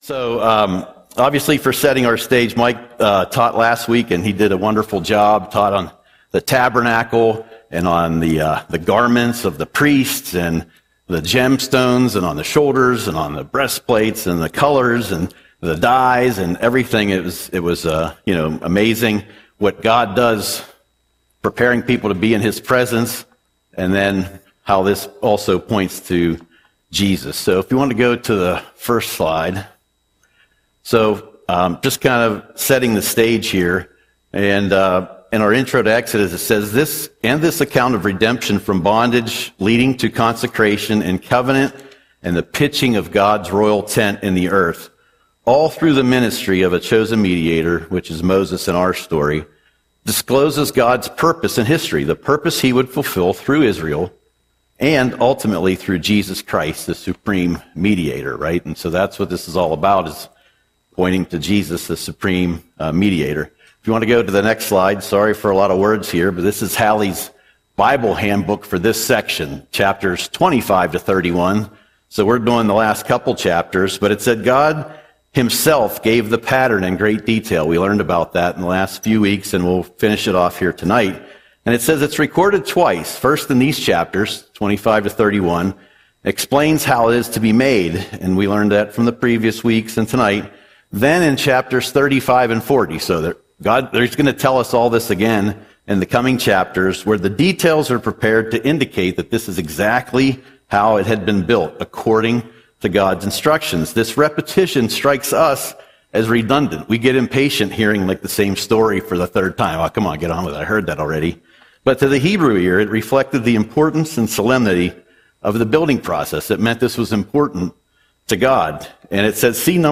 Audio Sermon - June 11, 2025